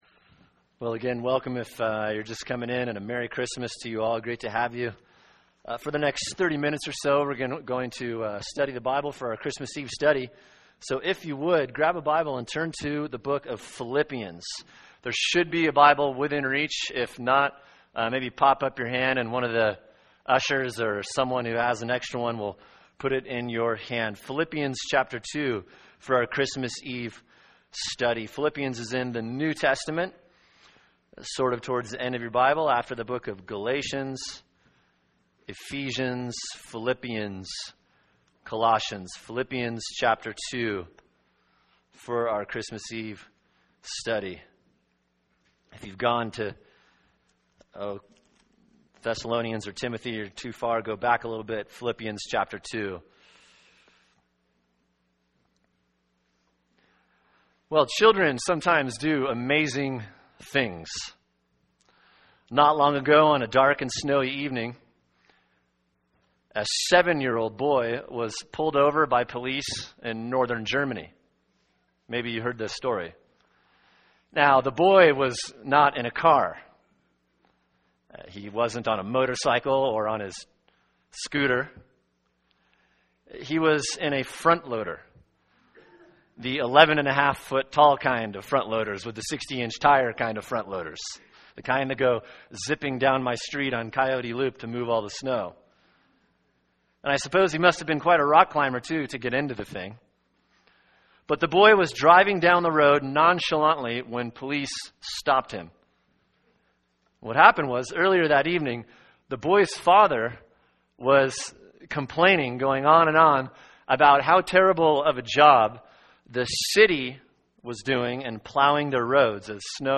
[sermon] Philippians 2:6-11 “He Humbled Himself” | Cornerstone Church - Jackson Hole
Christmas Eve Service.